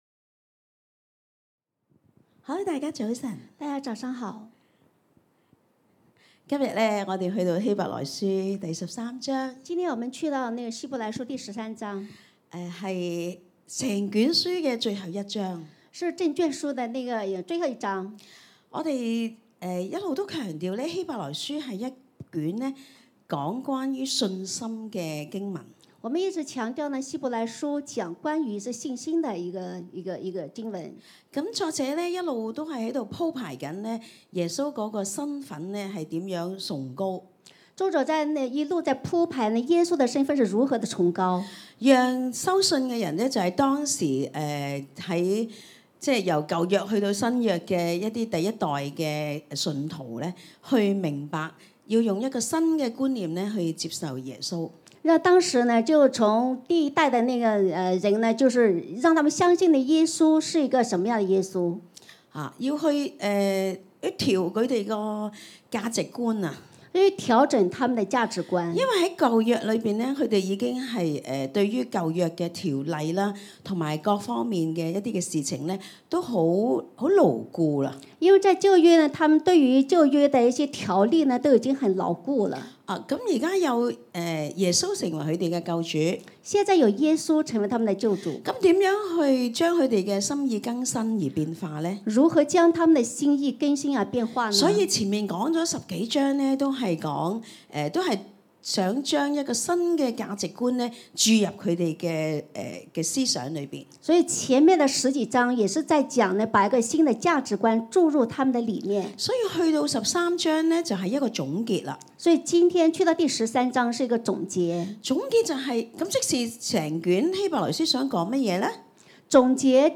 弟兄姊妹到台前分享讚美。